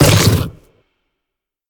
biter-roar-big-5.ogg